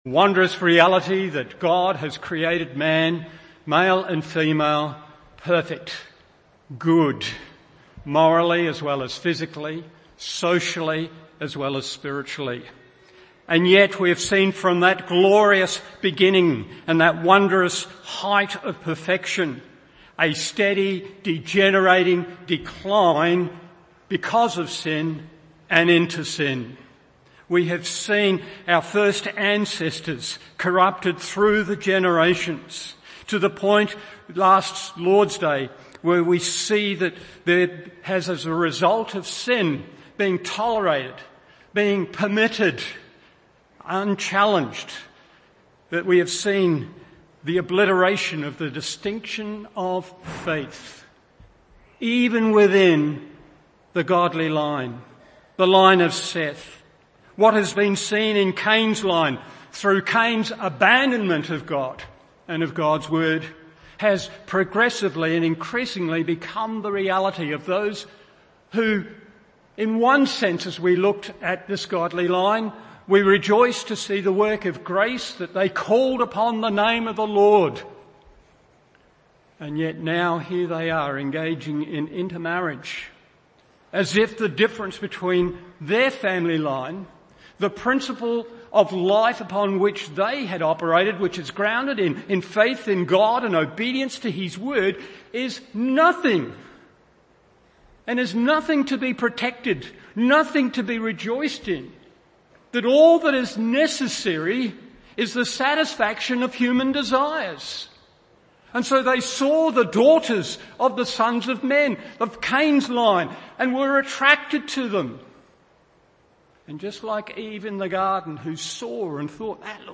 Morning Service Genesis 6:4-8 1.